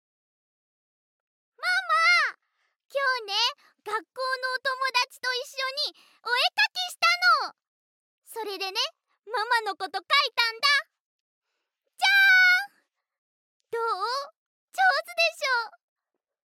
ボイスサンプル
幼女